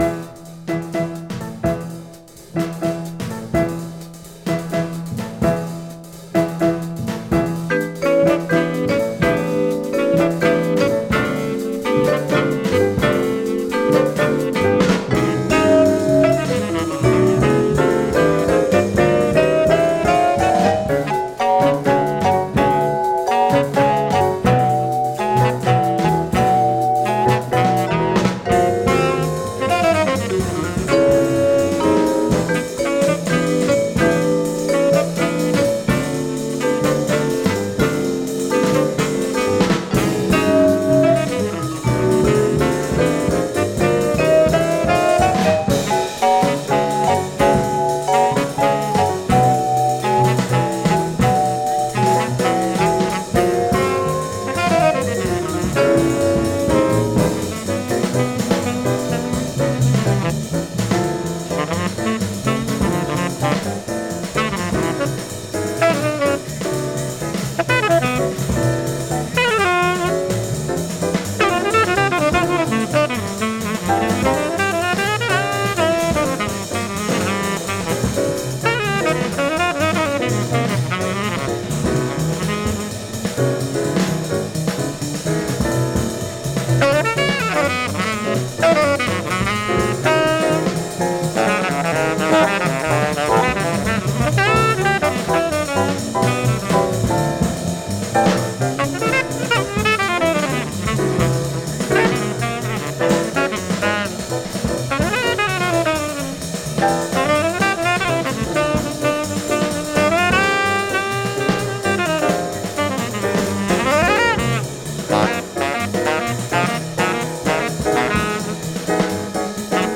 tenor sax
vibes
piano
bass
drums